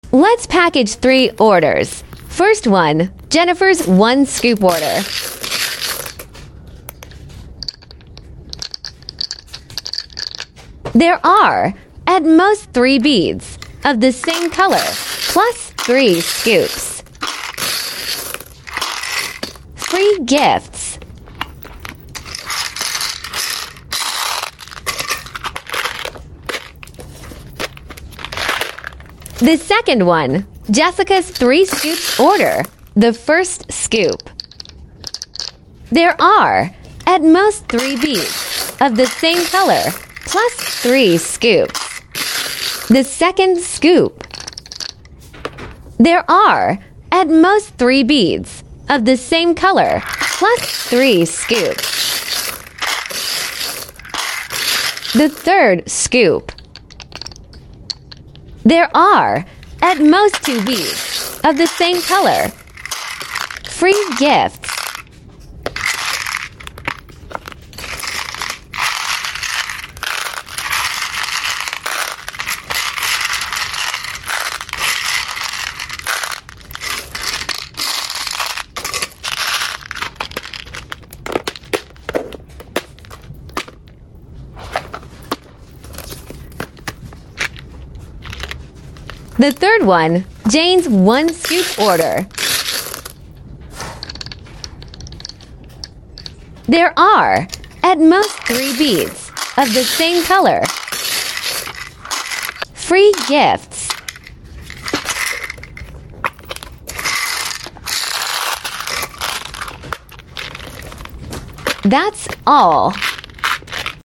Asmr packaging video!!! Guess how